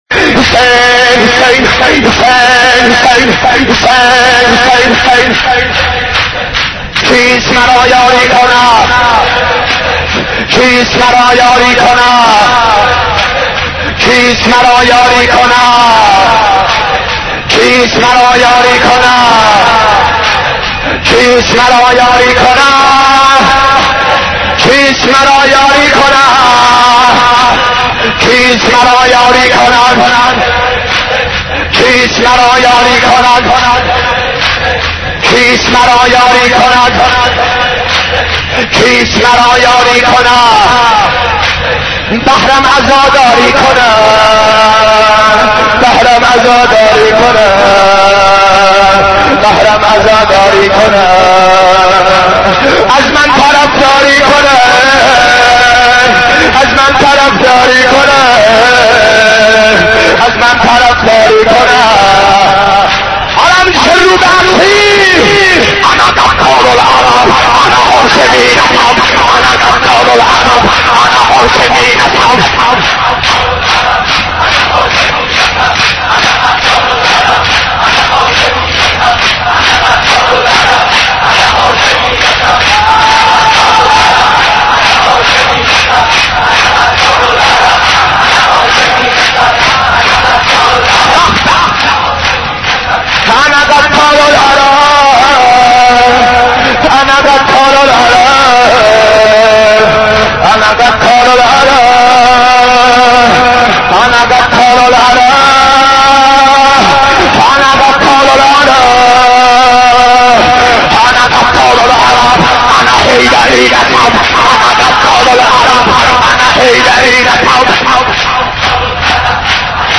حضرت عباس ع ـ شور 26